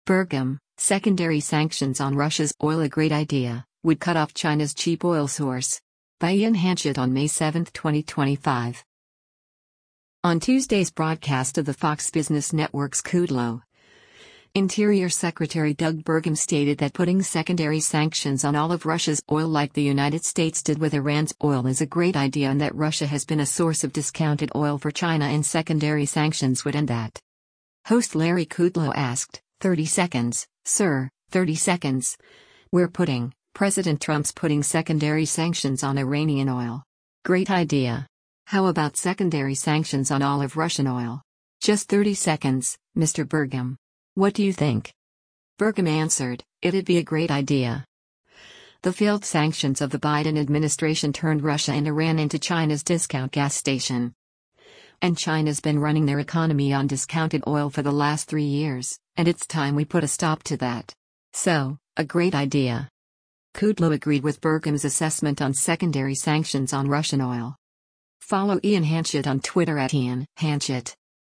On Tuesday’s broadcast of the Fox Business Network’s “Kudlow,” Interior Secretary Doug Burgum stated that putting secondary sanctions on all of Russia’s oil like the United States did with Iran’s oil is “a great idea” and that Russia has been a source of discounted oil for China and secondary sanctions would end that.